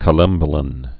(kə-lĕmbə-lən)